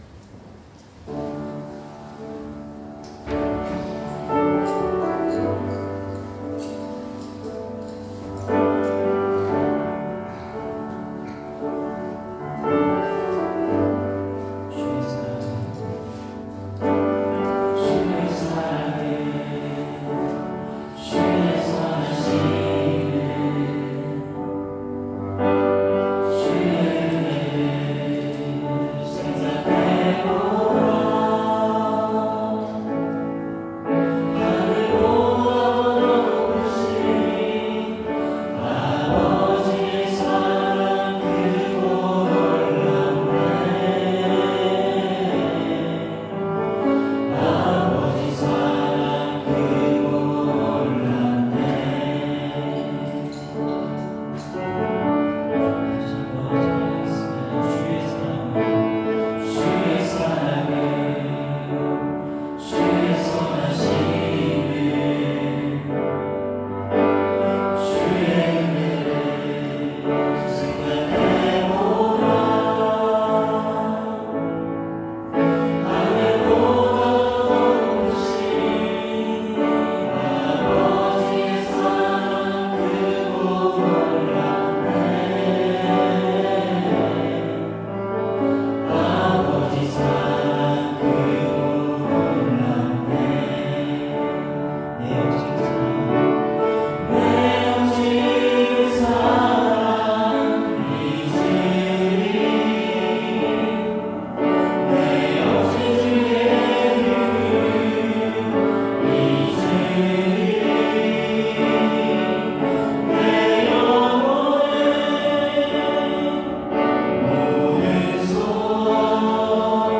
2026년 01월 18일 주일찬양